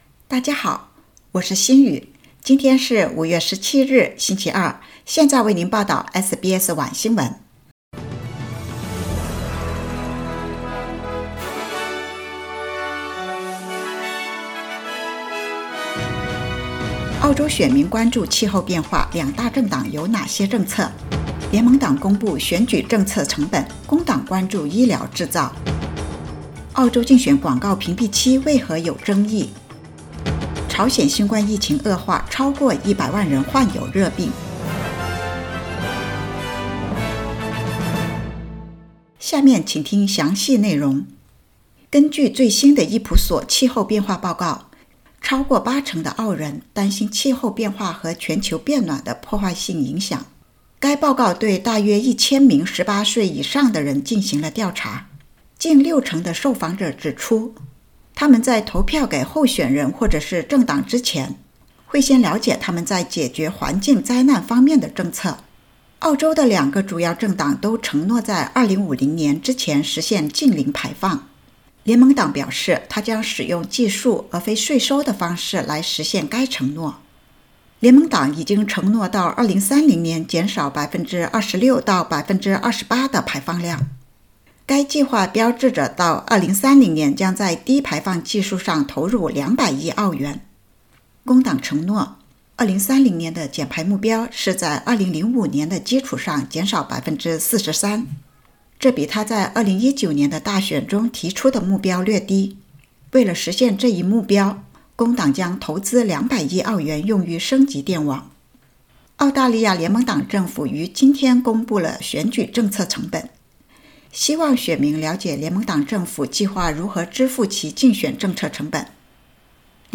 SBS晚新闻（2022年5月17日）
SBS Mandarin evening news Source: Getty Images